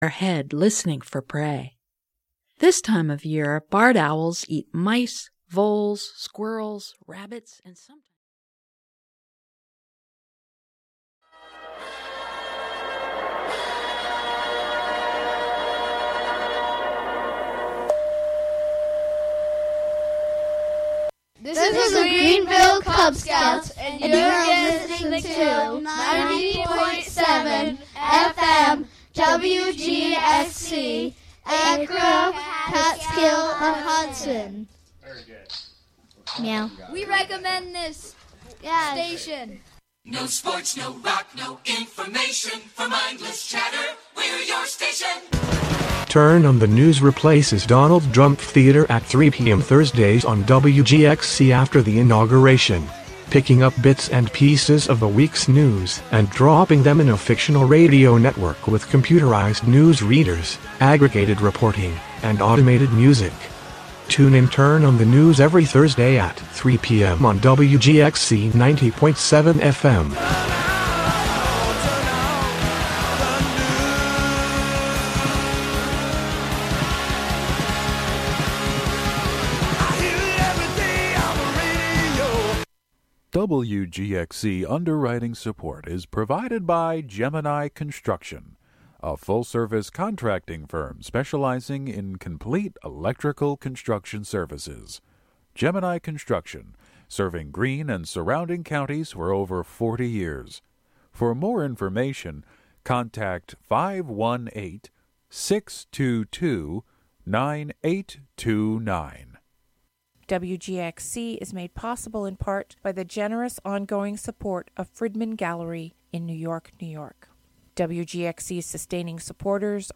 In this broadcast, a conversation